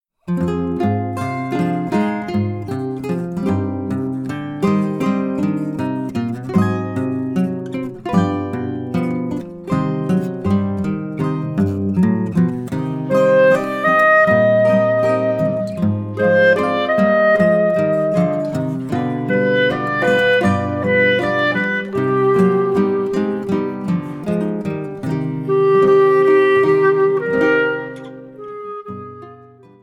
clarinet
Choro ensemble in the other songs